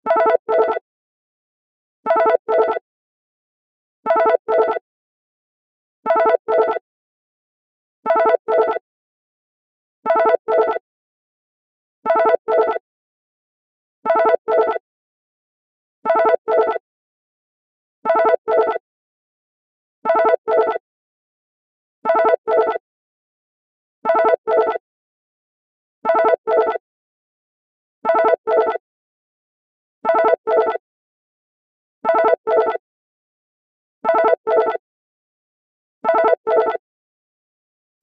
洗練されたシンプルさが魅力的で、ビジネスの中での優雅な瞬間を感じさせてくれます。